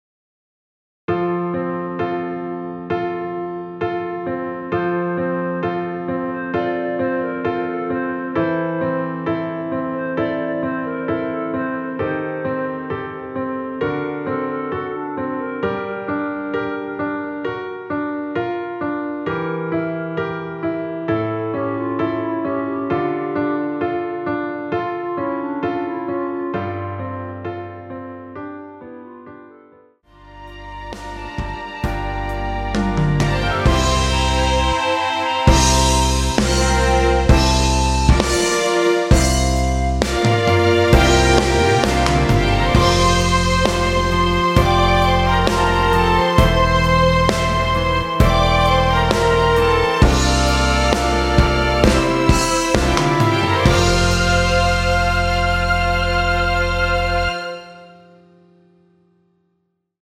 전주 없이 시작하는 곡이라 전주 1마디 만들어 놓았으며
원키에서(+5)올린 멜로디 포함된 MR입니다.(미리듣기 확인)
앞부분30초, 뒷부분30초씩 편집해서 올려 드리고 있습니다.
(멜로디 MR)은 가이드 멜로디가 포함된 MR 입니다.